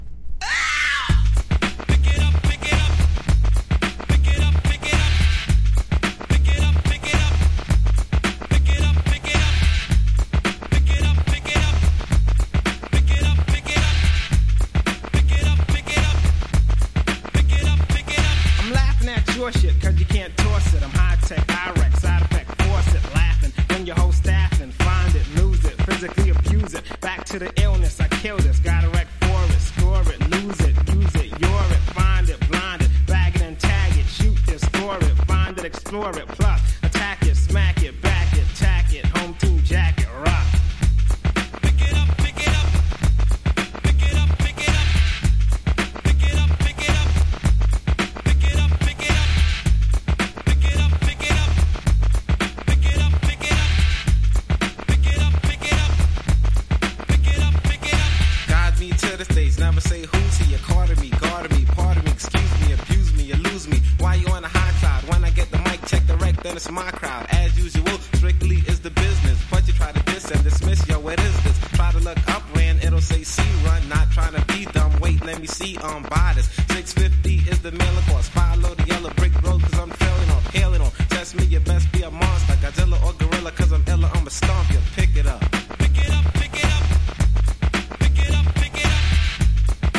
マイアミ産MED。
90’S HIPHOP